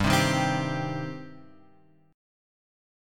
G Suspended 4th Sharp 5th